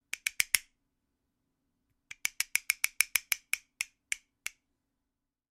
Звуки кастаньет
Звук кастаньет семпл